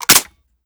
lowammo_indicator_shotgun.wav